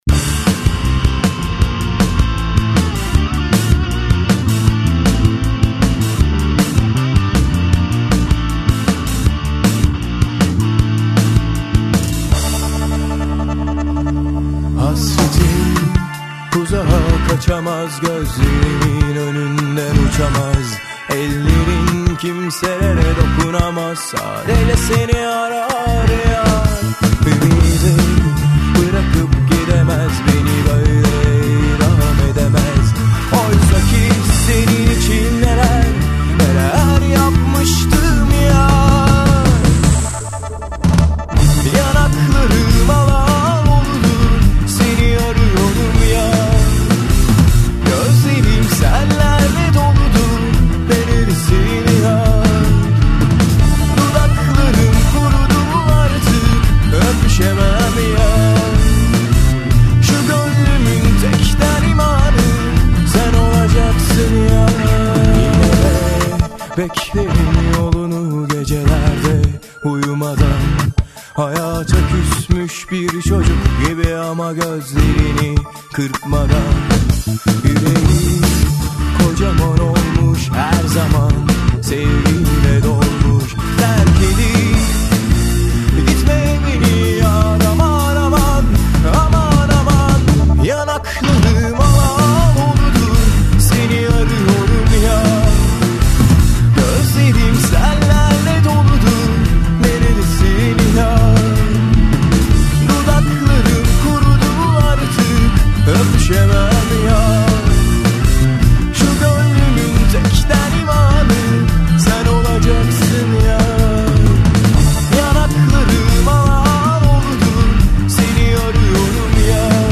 Modern Turkish Music
Alternative rock